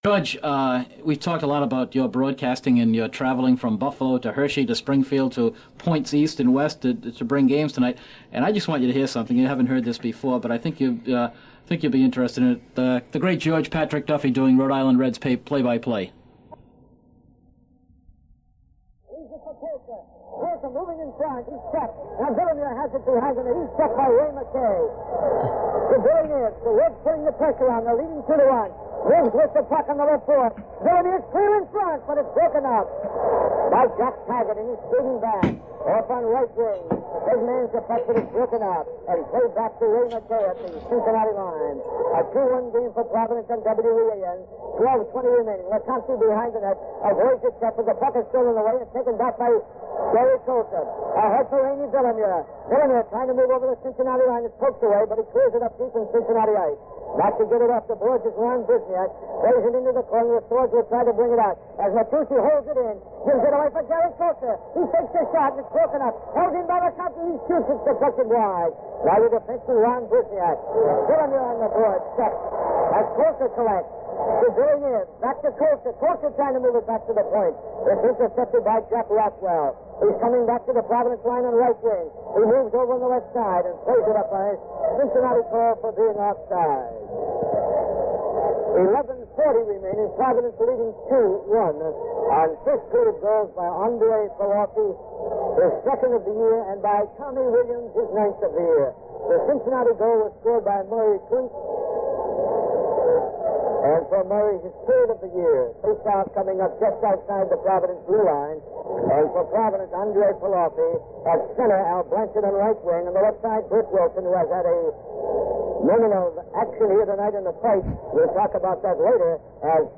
Radio Interviews – RI Reds Heritage Society